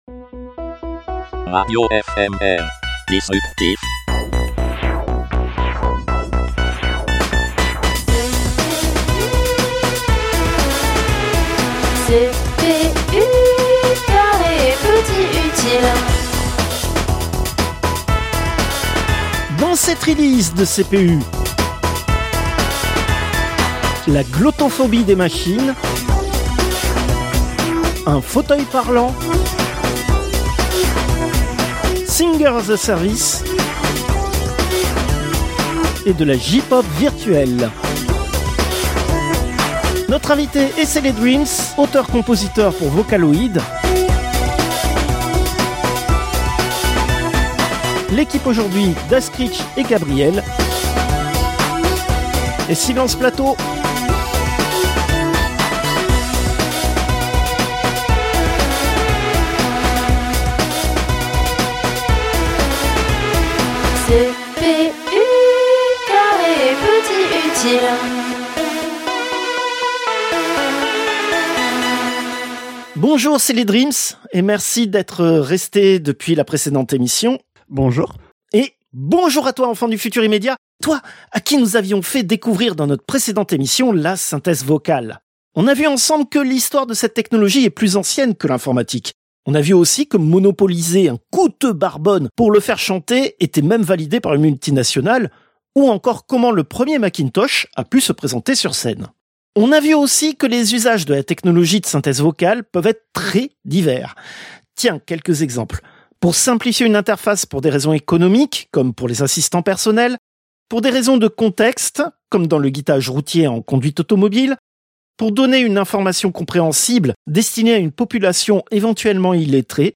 Interview, troisième partie